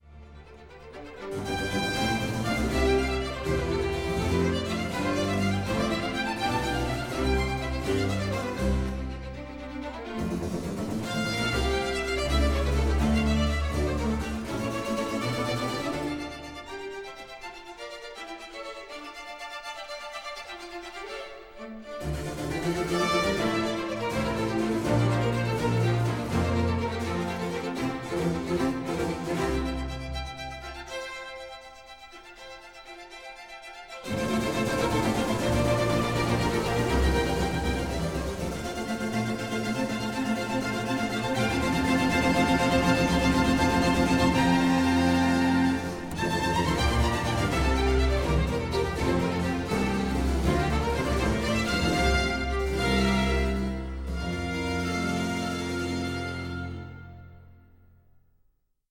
Acte de ballet (1748)